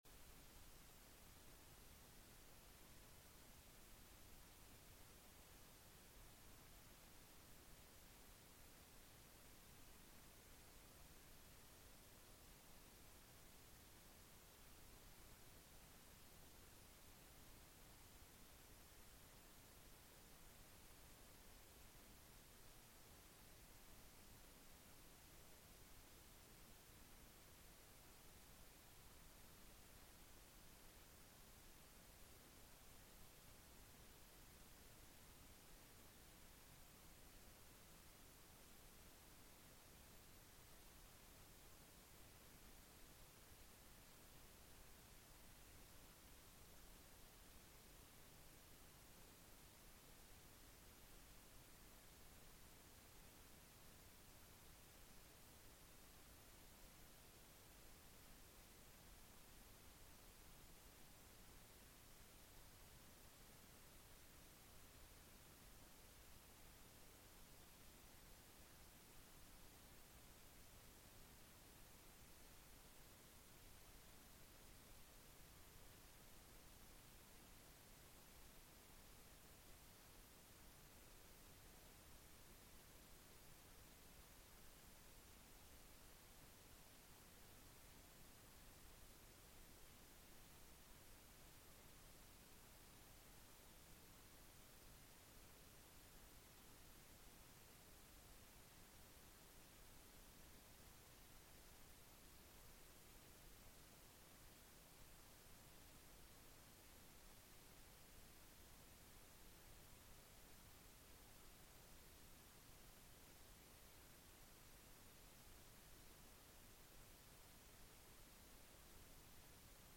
Sermons preached at the English Congregation of Church of the Good Shepherd (Singapore).